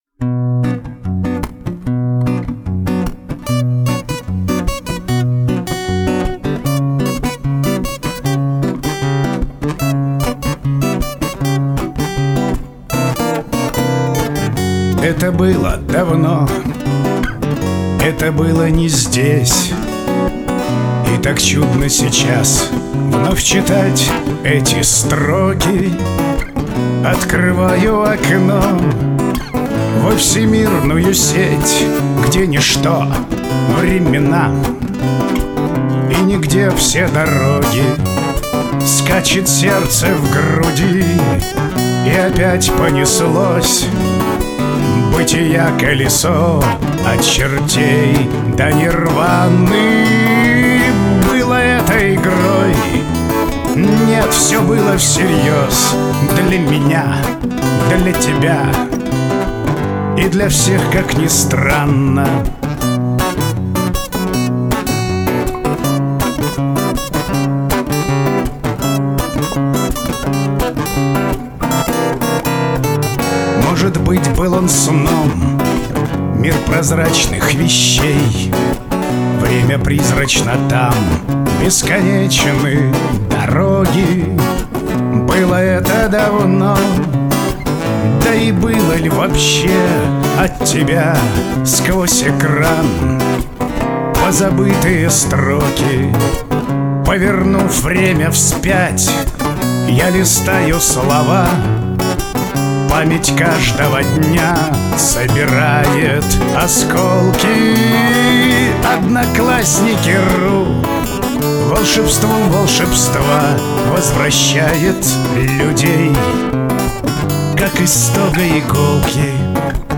Жанр: Авторская песня